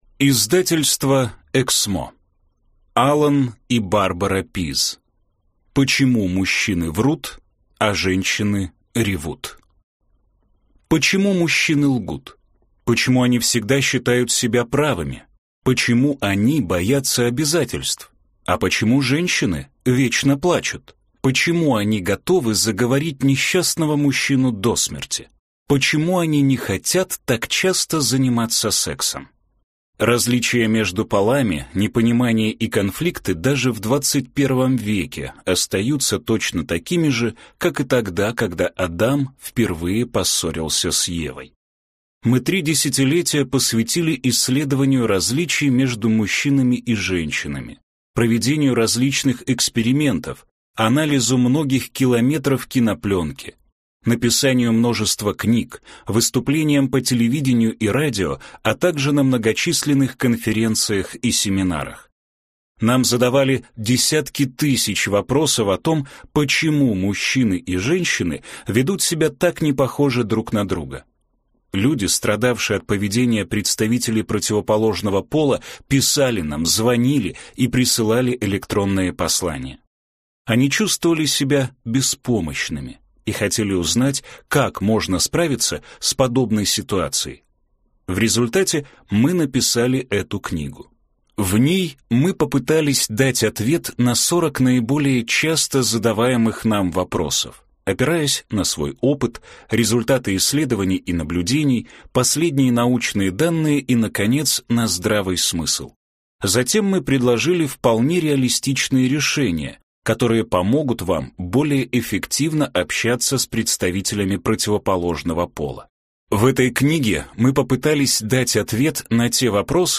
Аудиокнига Почему мужчины врут, а женщины ревут | Библиотека аудиокниг